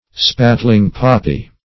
Search Result for " spattling-poppy" : The Collaborative International Dictionary of English v.0.48: Spattling-poppy \Spat"tling-pop"py\, n. [Prov.
spattling-poppy.mp3